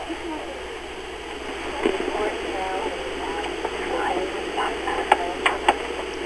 Weather:  Rain on & off - but investigation was conducted inside
Analog Audio Recorder with external mic
Cranbury_Inn_attic_EVP.WAV